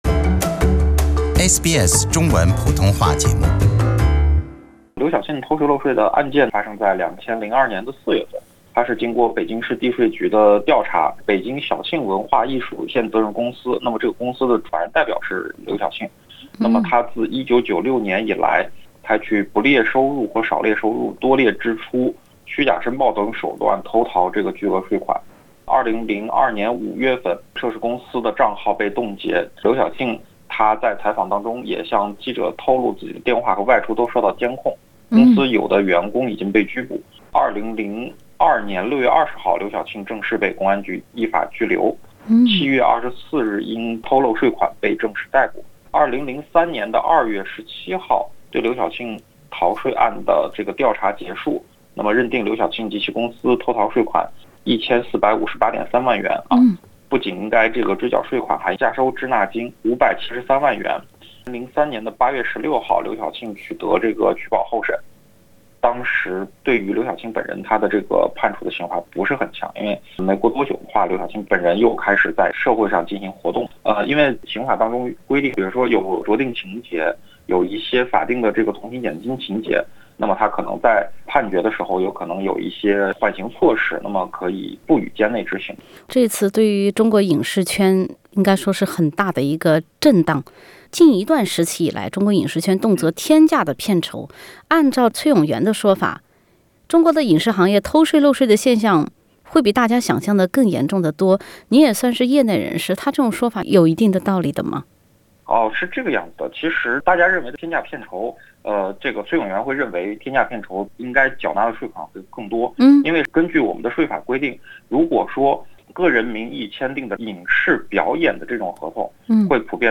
Interview with…